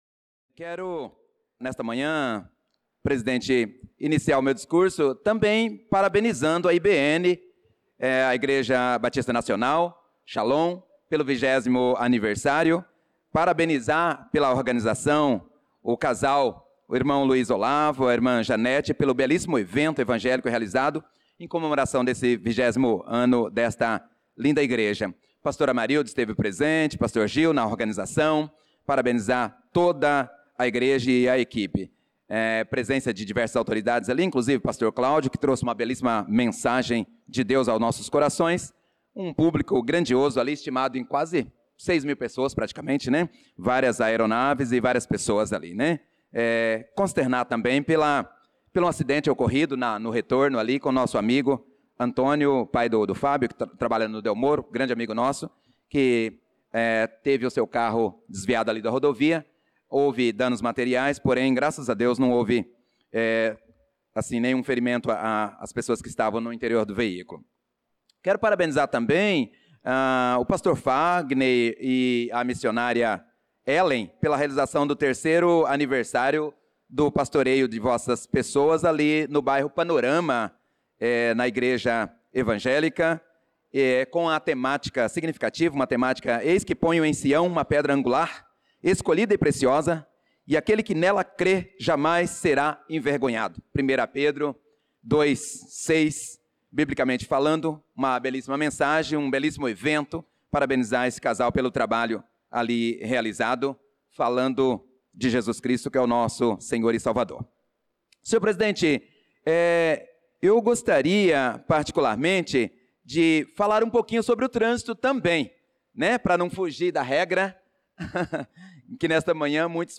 Pronunciamento do vereador Prof. Nilson na Sessão Ordinária do dia 18/08/2025.